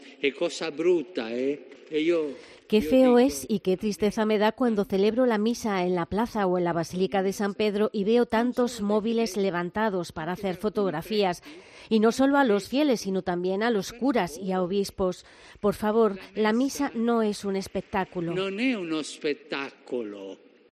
Así improvisó hoy Jorge Bergoglio mientras leía su catequesis en la audiencia general celebrada como cada miércoles en la Plaza de San Pedro y que estaba dedicada a la Eucaristía.